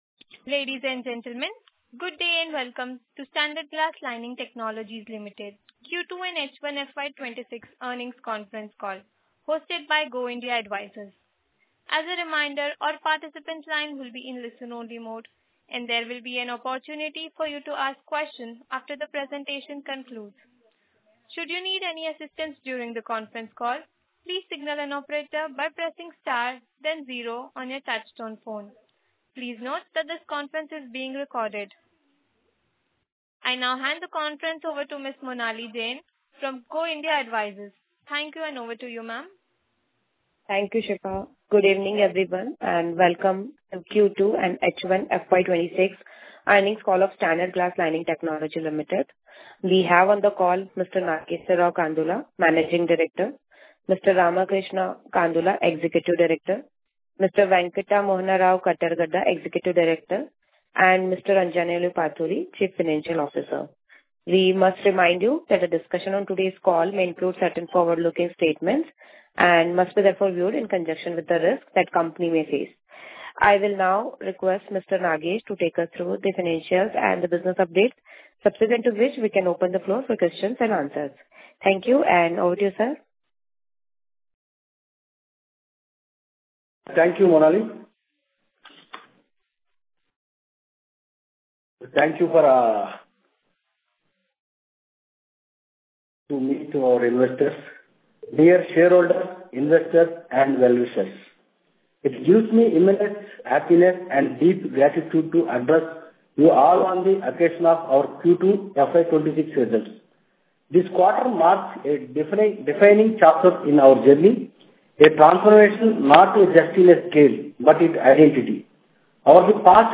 Tejas Networks Tejas Networks Q4 FY26 earnings call held on April 15, 2026 Posted: 16 Apr 2026 Azad Engineering Azad Engineering Audio recording link of earnings call for Q3 and nine months ended Dec 31, 2025, organized Feb 14, 2026.